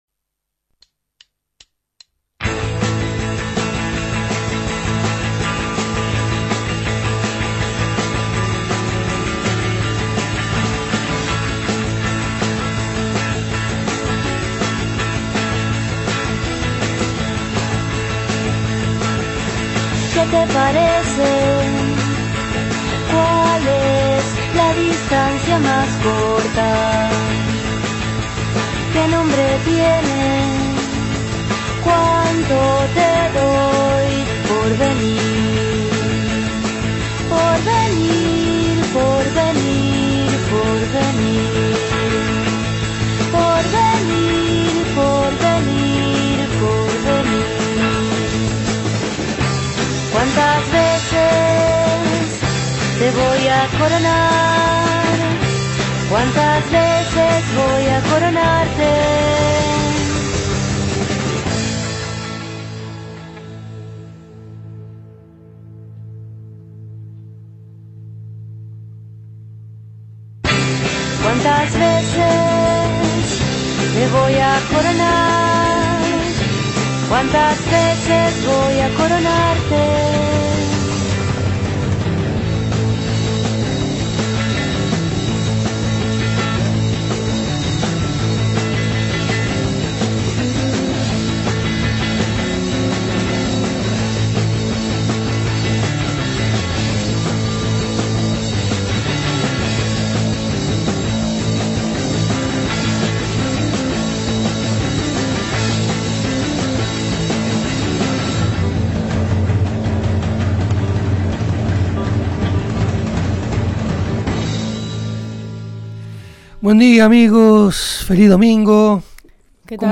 Letra y Música
Escuchamos canciones de este último trabajo discográfico.